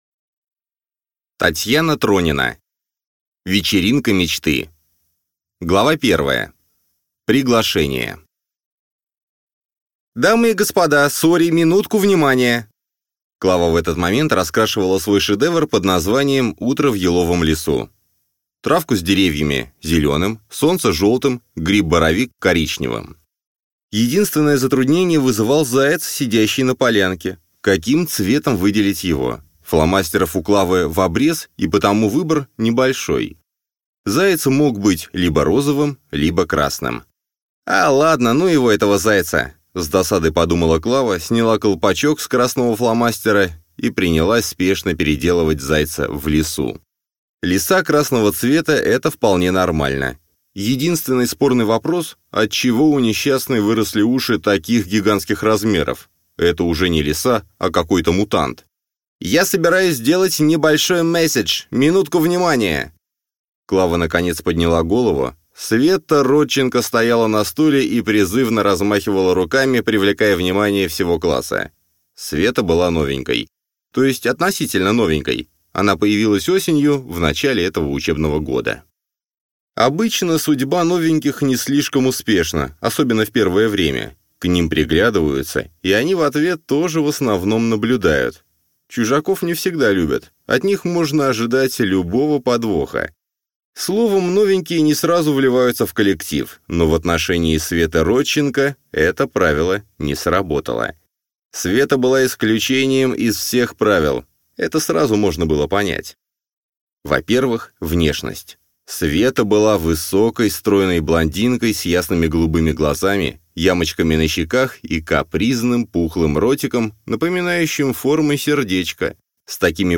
Аудиокнига Вечеринка мечты | Библиотека аудиокниг
Прослушать и бесплатно скачать фрагмент аудиокниги